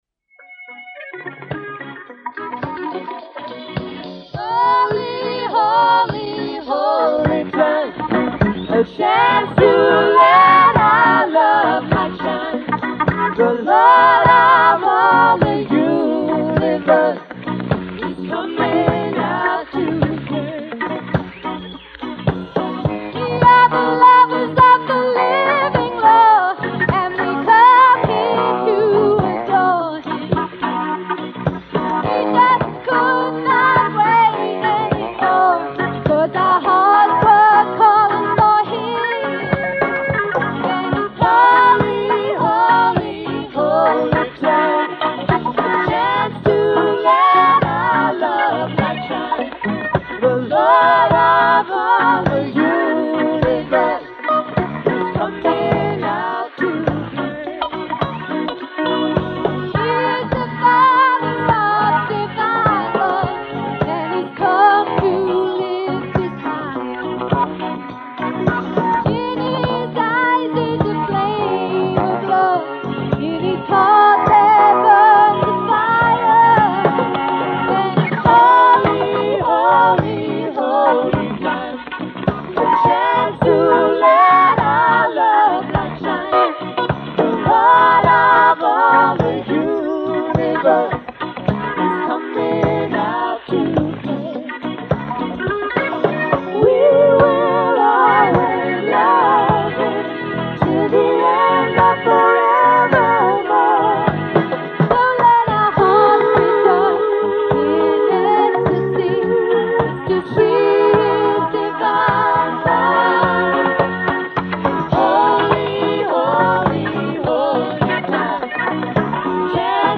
1980 BHAJANS RECORDED AT THE HOLI 1980 FESTIVAL